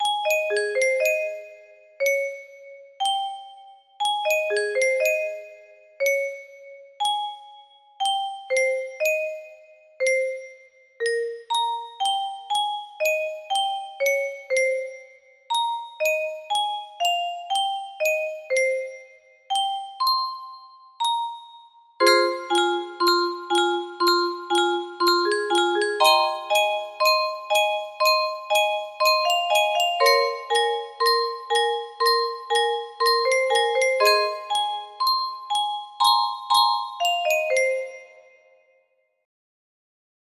Clone of Unknown Artist - Untitled music box melody